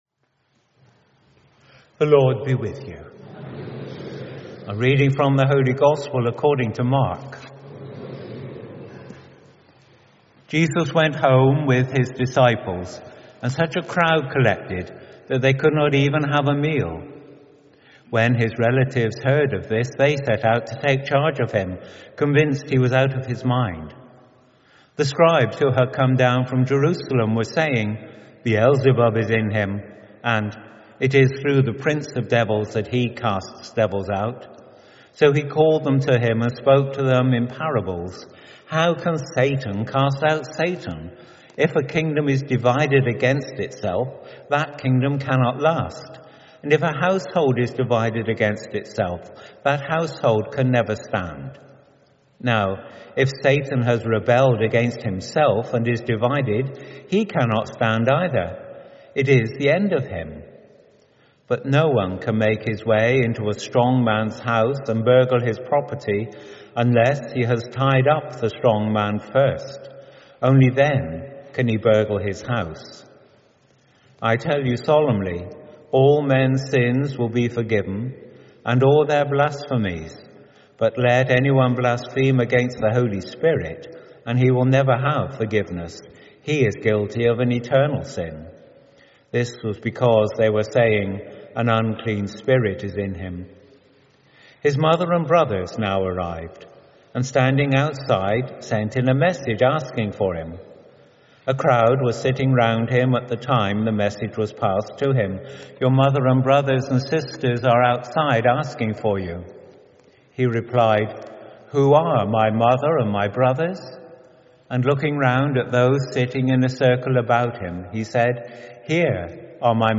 Homilies Liturgy Year B, Mark, from Penzance Catholic Church, Holy Family Parish